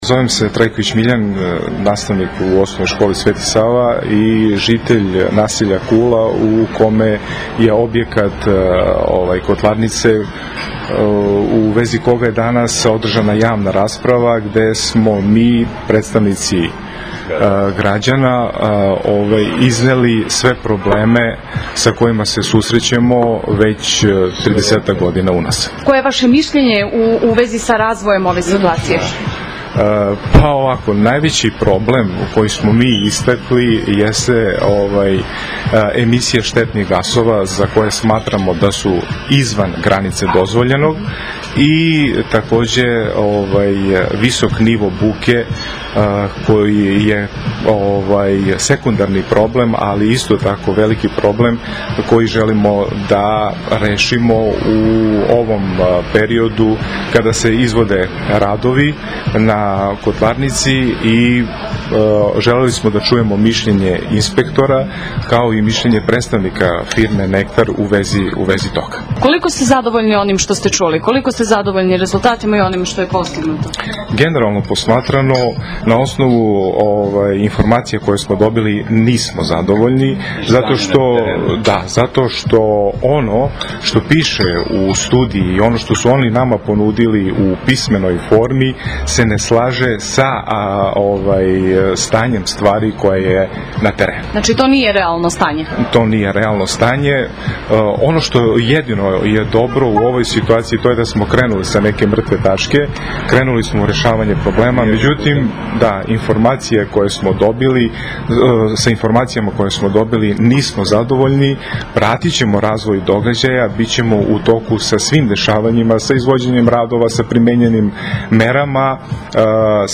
Nakon javne rasprave, razgovarali smo sa jednim od predstavnika građana o dosadašnjim rezultatima u vezi sa ovom temom.